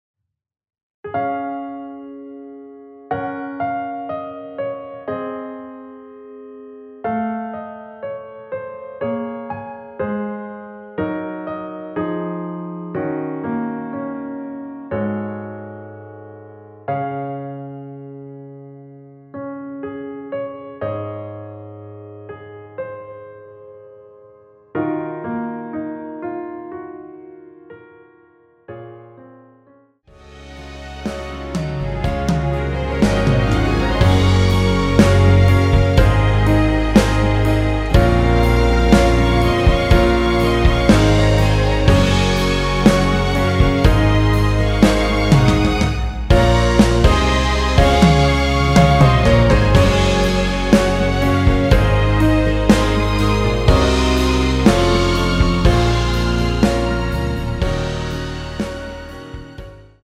남성분이 부르실수 있는 키로 제작 하였습니다.(미리듣기 참조)
Db
앞부분30초, 뒷부분30초씩 편집해서 올려 드리고 있습니다.